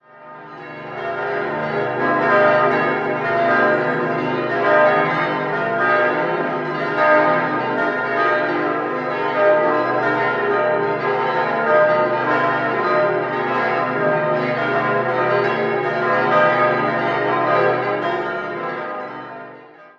9-stimmiges Geläute: c'-es'-f'-as'-b'-c''-es''-f''-c'''